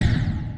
death.mp3